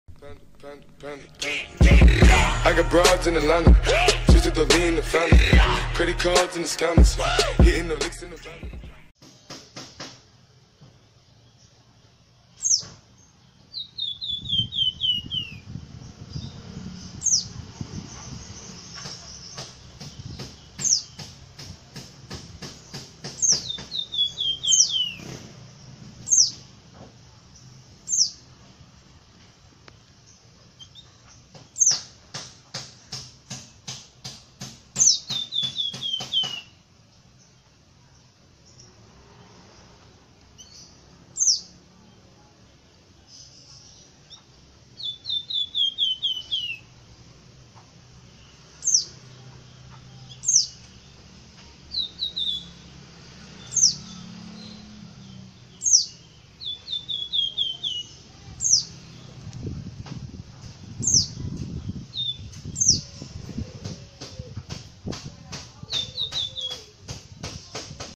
Suara Burung Wergan/ Flamboyan |